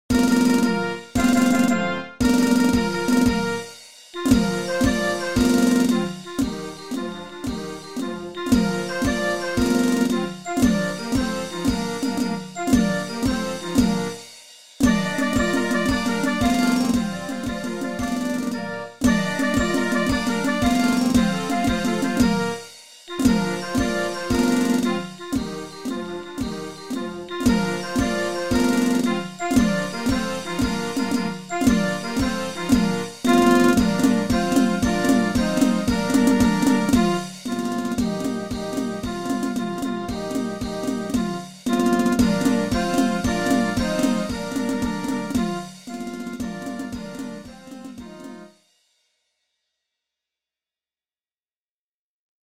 pour les Harmonies
airs connus (arrangement)